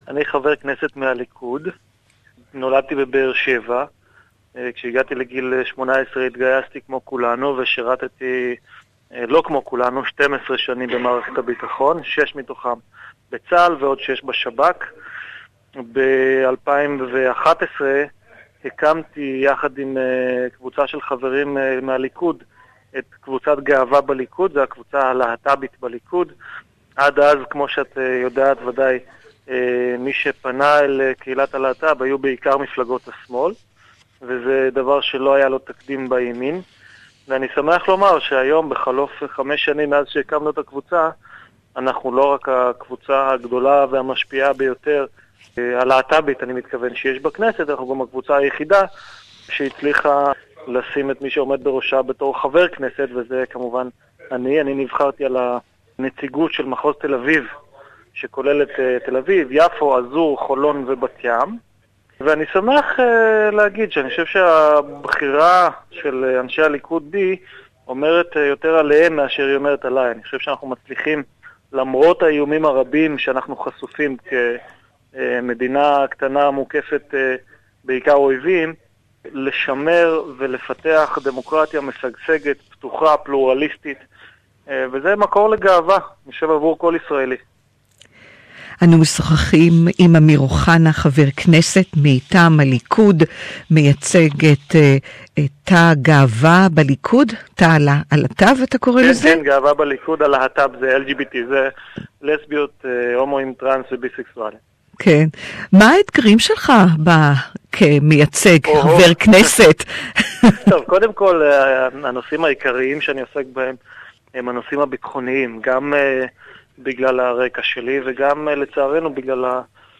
(Hebrew interview)